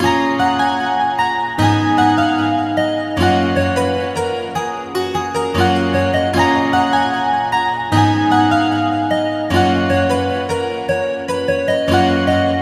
标签： 152 bpm Trap Loops Guitar Acoustic Loops 2.13 MB wav Key : Unknown
声道立体声